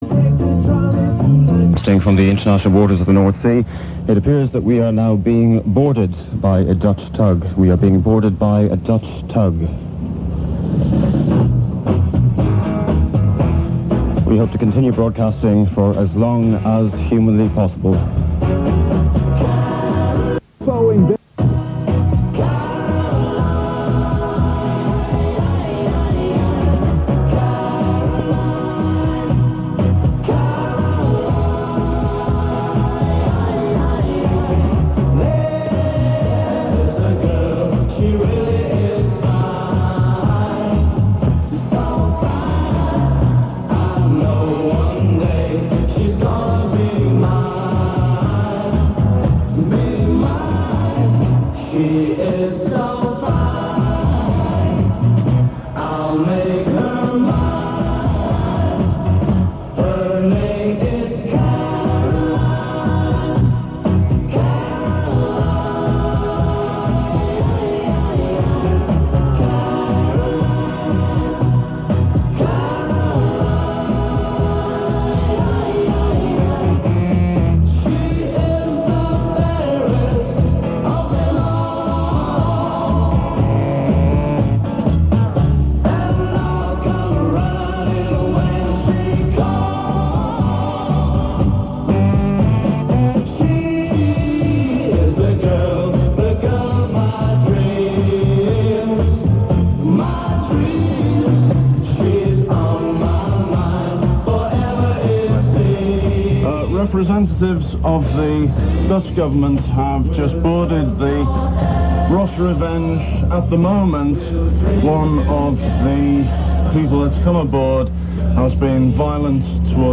While all this was going on listeners to both stations were kept informed by way of special announcements, but at 1.08pm both medium wave transmitters on the Ross Revenge fell silent and once again Radio Caroline had gone off the airwaves.
1. Announcing arrival of the Dutch tug Volans
1 announcing arrival of Dutch tug 19.08.89.mp3